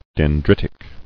[den·drit·ic]